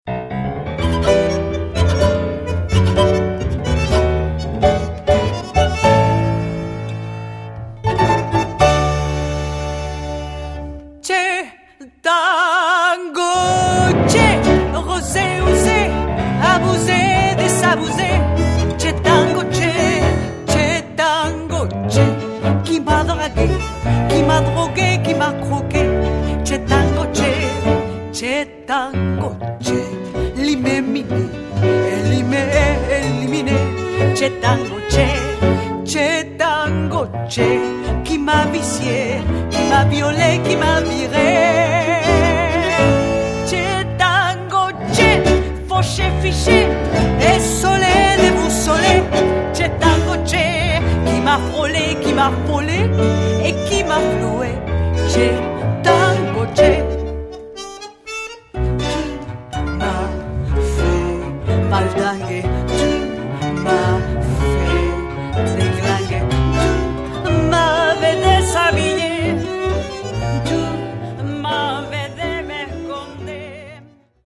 fonctionne actuellement comme un quintette de jazz
Guitare/chant/arrangement
Piano
Bandonéon
Violon alto
Contrebasse